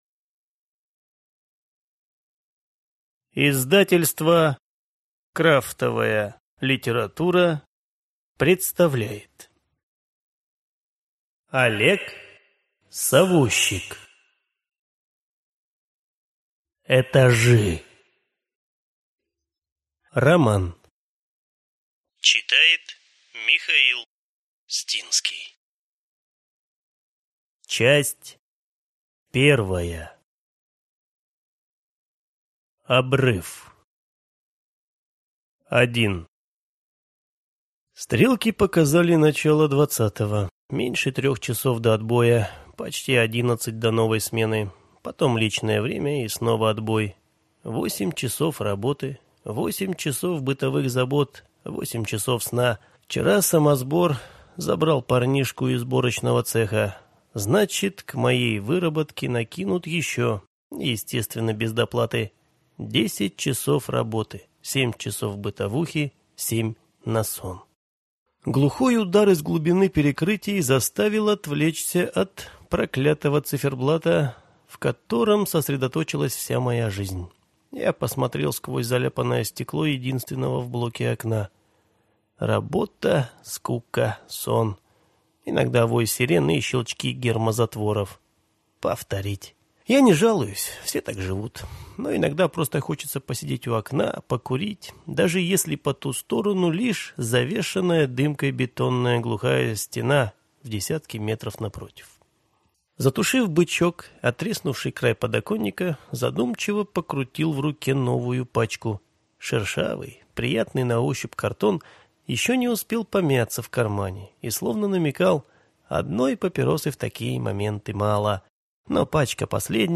Аудиокнига Этажи | Библиотека аудиокниг